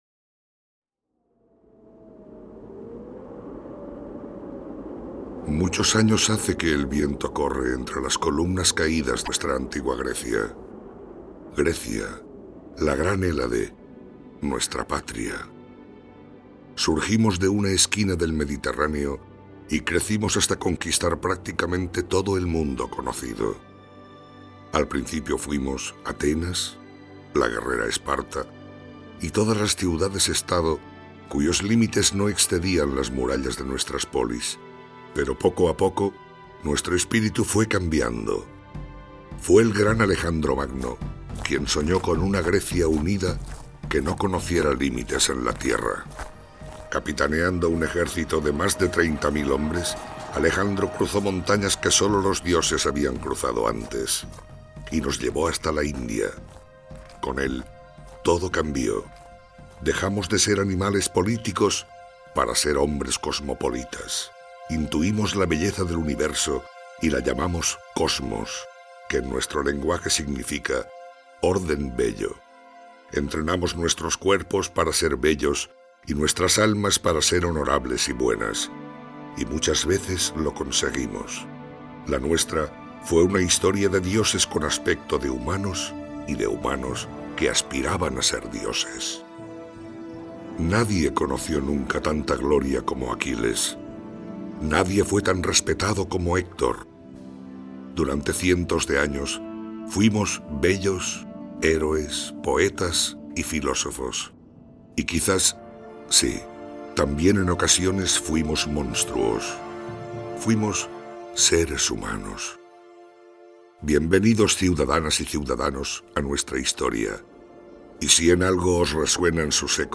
Audioguia
Para desarrollar las ideas, explicaciones y conceptos de “La belleza del cuerpo”, se han utilizado dos personajes ficticios que sirven de hilo conductor a lo largo del devenir de la exposición:
Uno es un viejo y tradicional griego, orgulloso y conocedor de su cultura, que vivió activamente la historia de su pueblo.
La narradora es expresamente una voz femenina, algo que hubiera sido contra natura por estar privadas de voz las mujeres en la mayoría de actos, eventos y sucesos trascendentales de la época de la antigua Grecia.
Soprano: Pilar Jurado